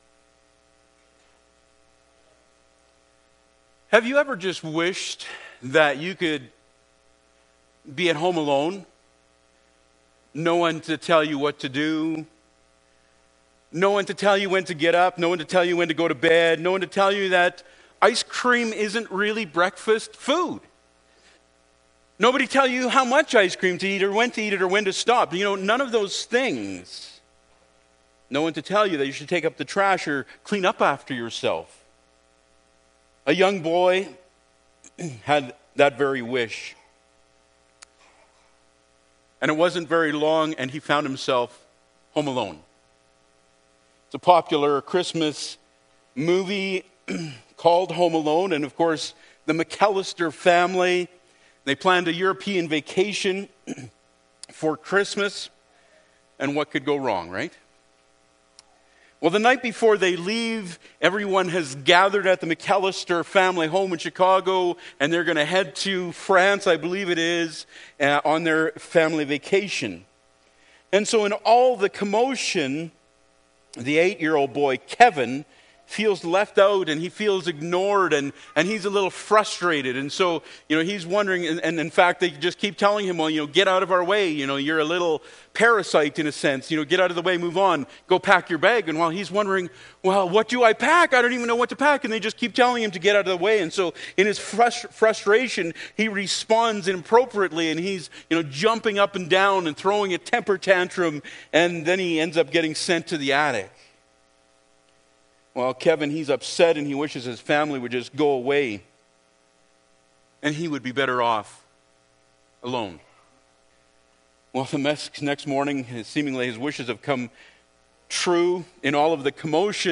Bible Text: Matthew 1:23 | Preacher